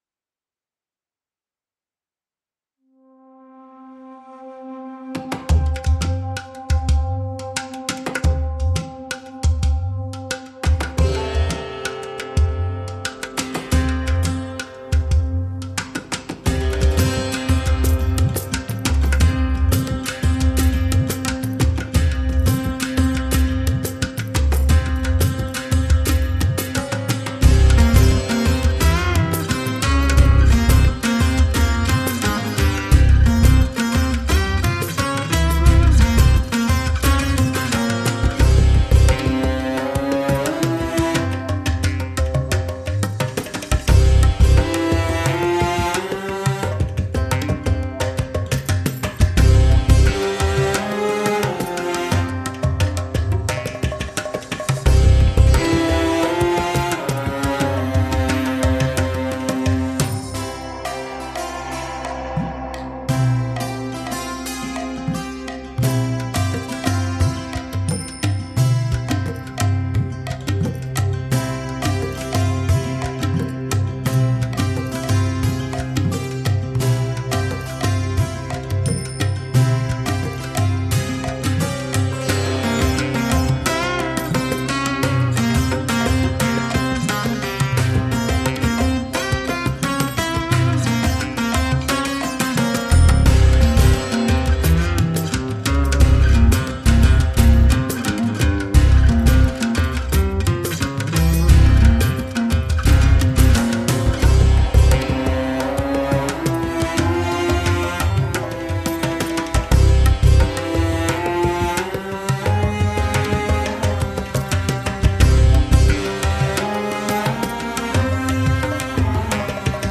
Современная музыка Индии